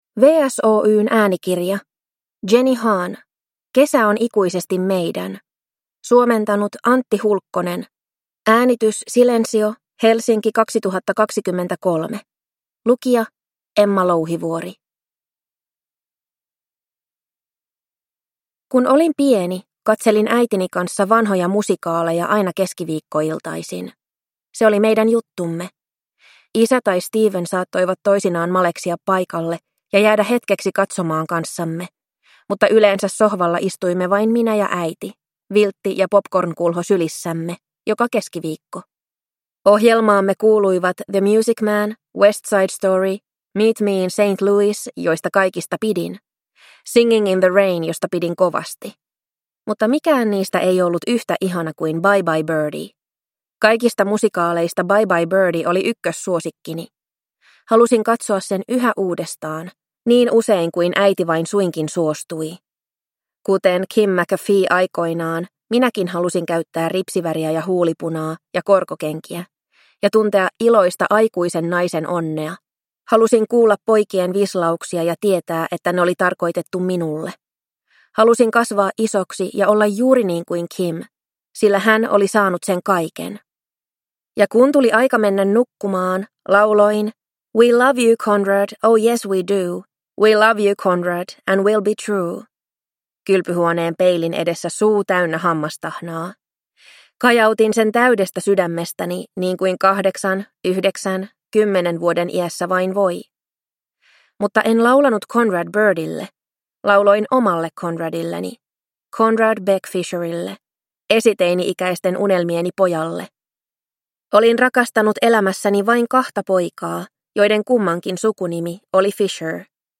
Kesä on ikuisesti meidän – Ljudbok – Laddas ner